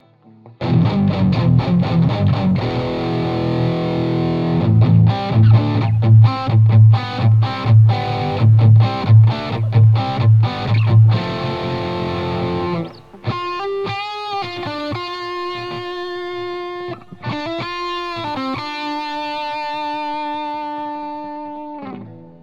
V BTE  Audio High Gain Amplifier  som stiahol Basy z 3/10 na 0,7/10 a vysky naplno, plus za konvoluciou jeden EQ s pridanymi vyskami len tak od oka.